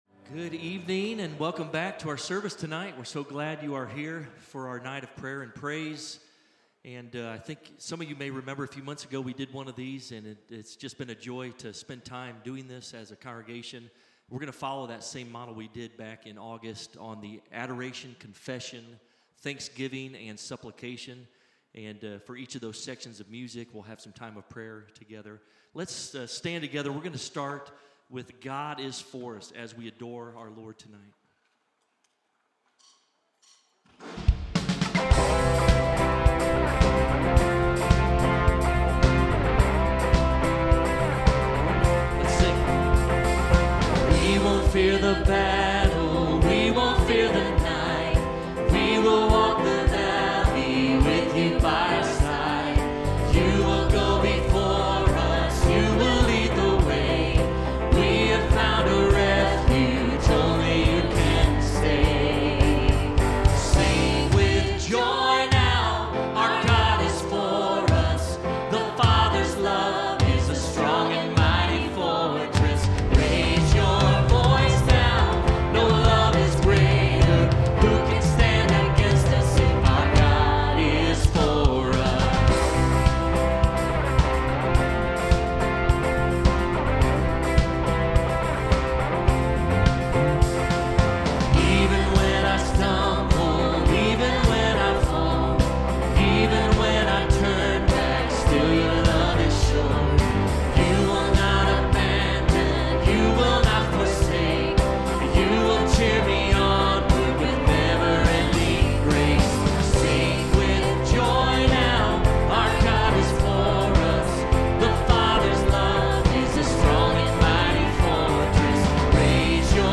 Praise and Prayer Night